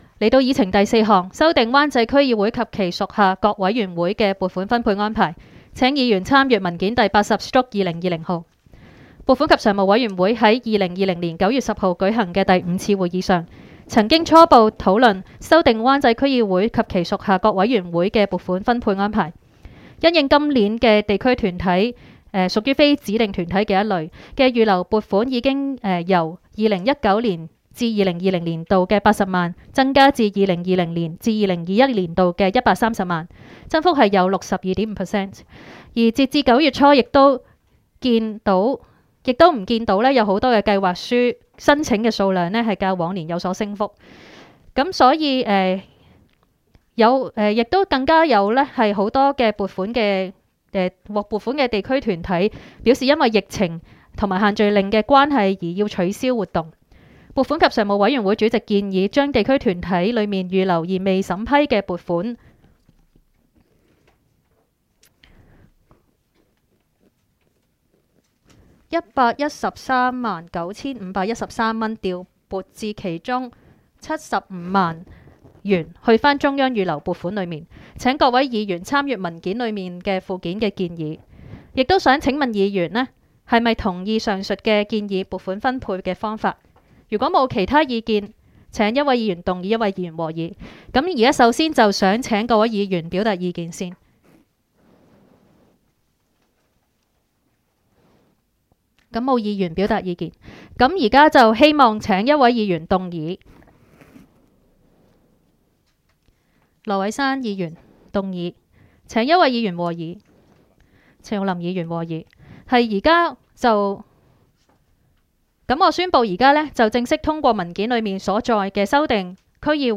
湾仔区议会会议室